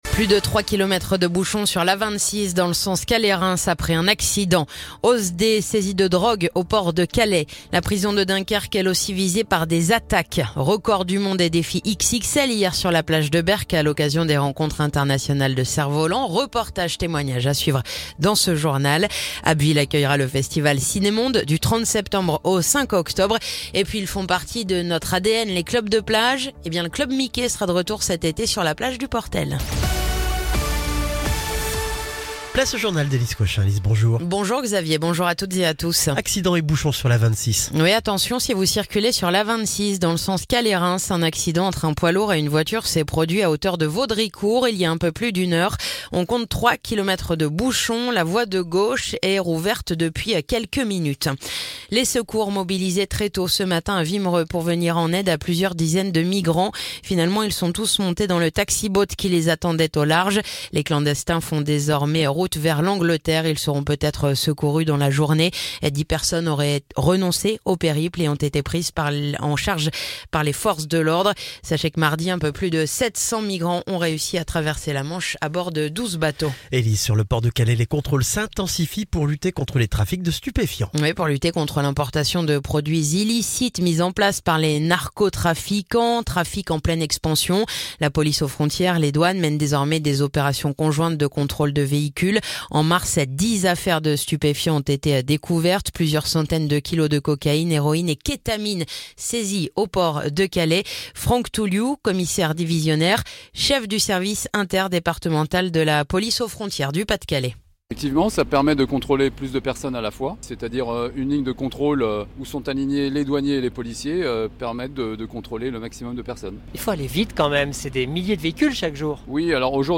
Le journal du jeudi 17 avril